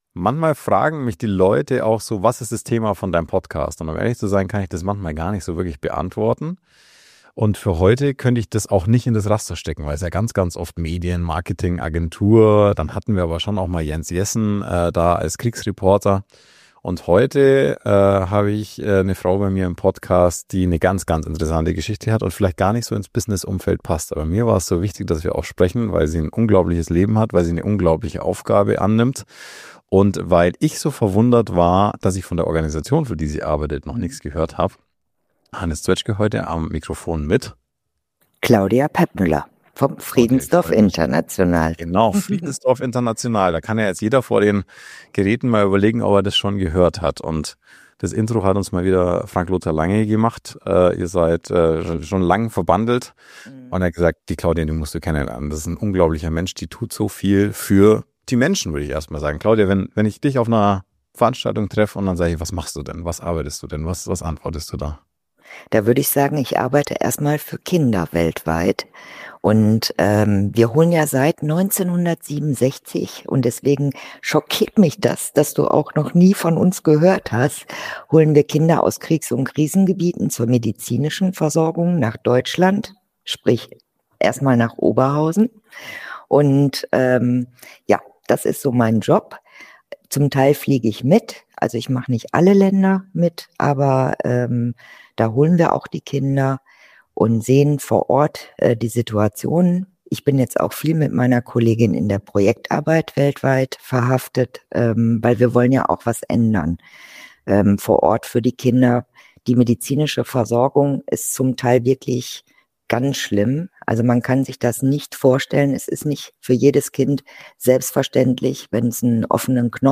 Es ist ein Gespräch, das mich berührt und gleichzeitig geerdet hat.